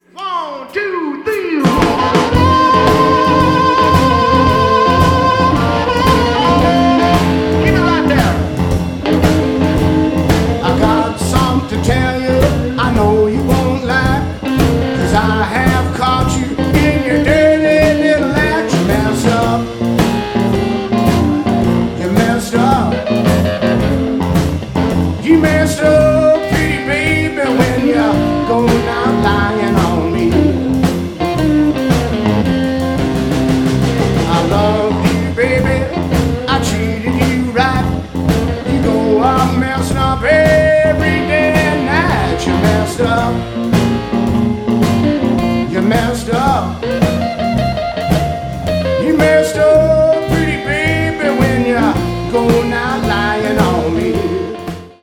recorded live at Moe's Alley in Santa Cruz, California